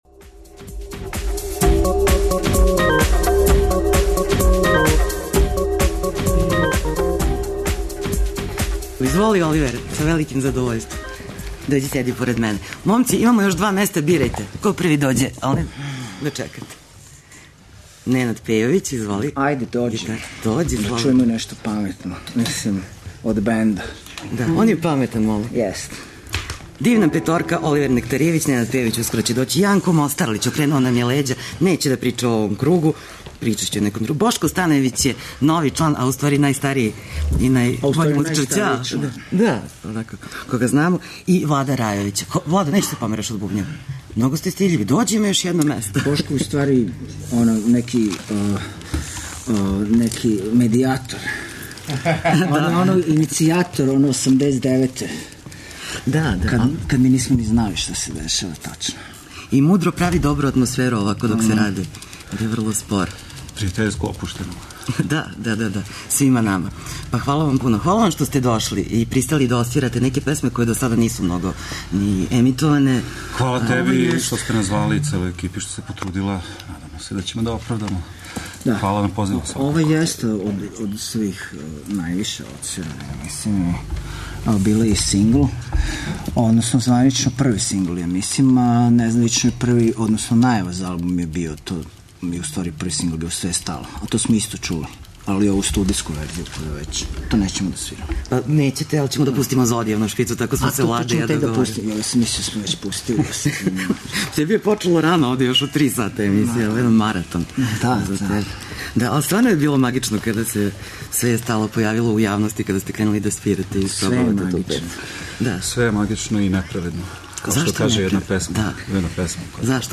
Свирају уживо у Студију 6!